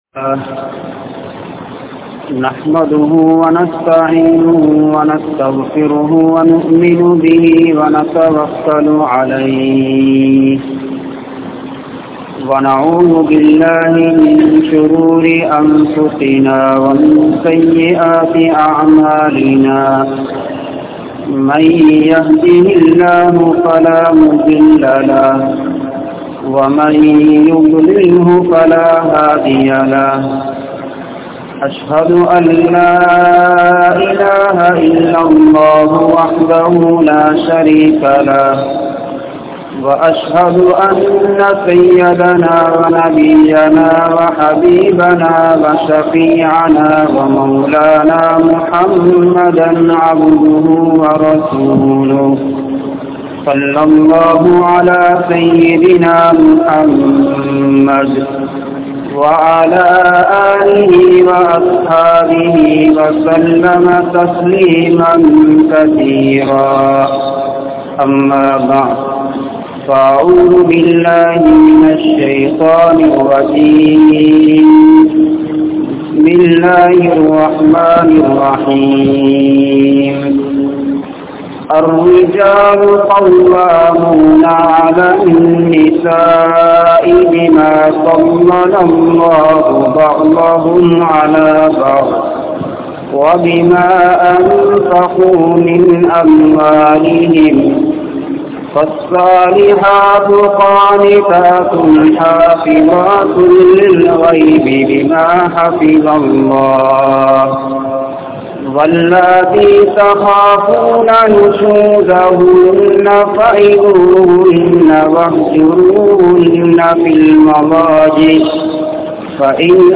Aniyaayam Ungalai Aliththu Vidum (அநியாயம் உங்களை அழித்து விடும்) | Audio Bayans | All Ceylon Muslim Youth Community | Addalaichenai
Grand Jumua Masjith